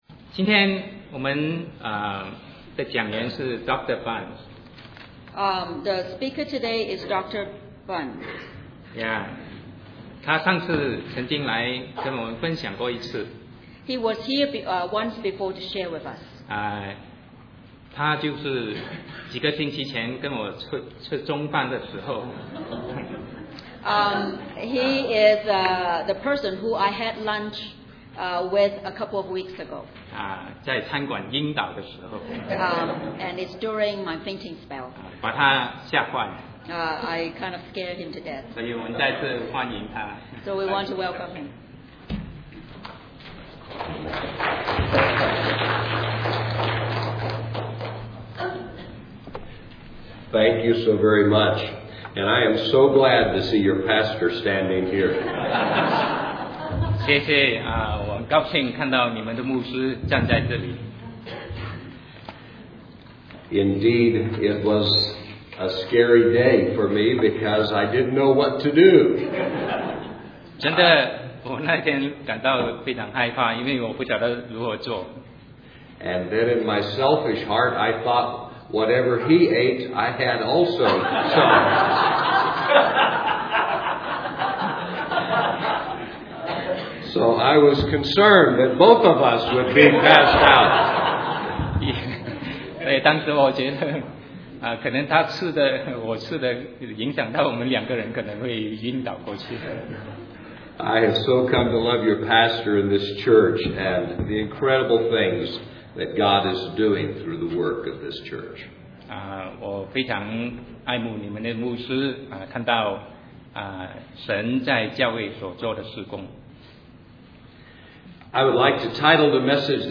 Sermon 2009-02-22 Christ and the Incurable